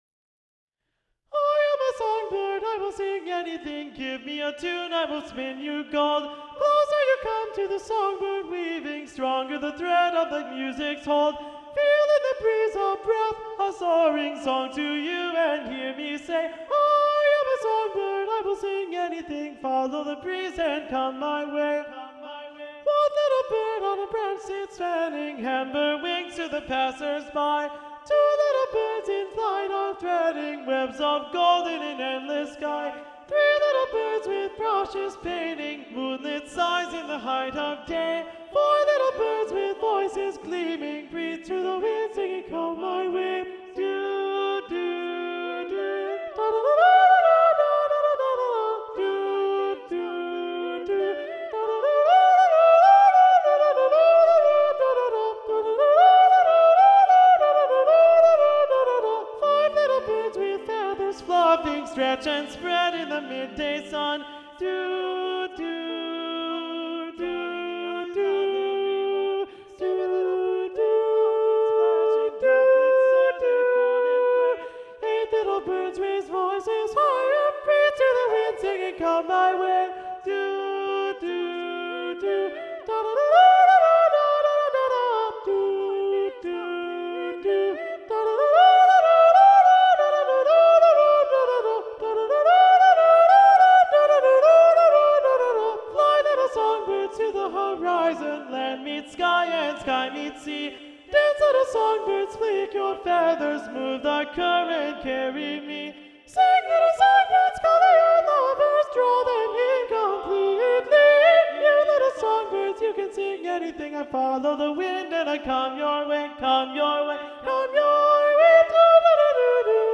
Soprano 1